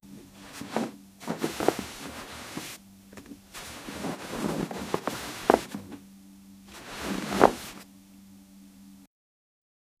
A sound effect of lightly, but fast, moving on the bed and bed sheets.
a-sound-effect-of-lightly-tdn6csgx.wav